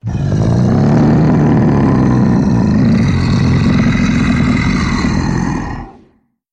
Звуки великана
Здесь собраны впечатляющие аудиоэффекты: гулкие шаги, низкие рыки, скрип древних деревьев под тяжестью гигантов.